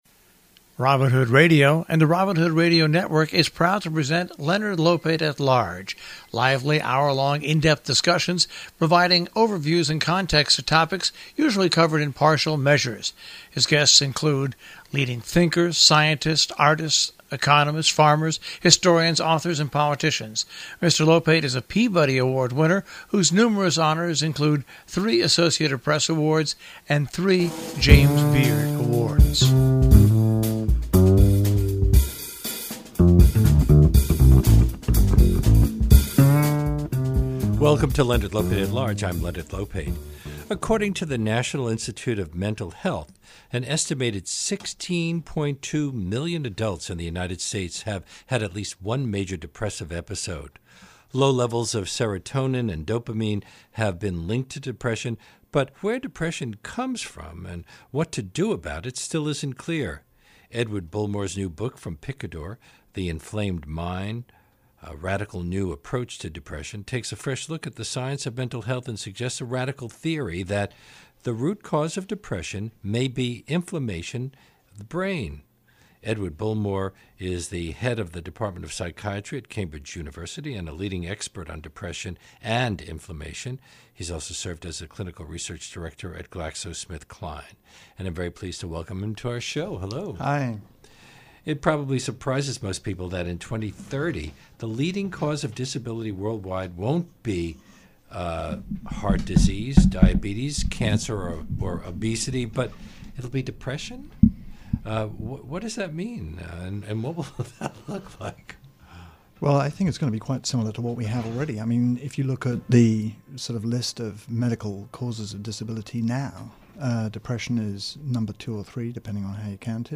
Today on Leonard Lopate at Large, Leonard has a conversation with Edward Bullmore discussing the science behind the methodology he outlined in his new book The Inflamed Mind .